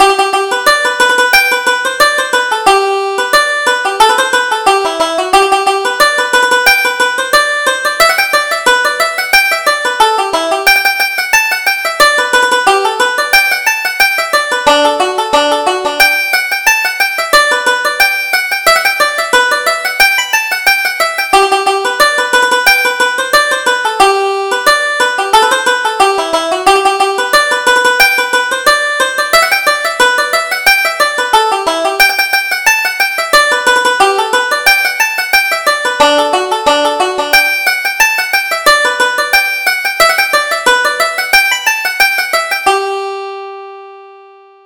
Reel: The Long Strand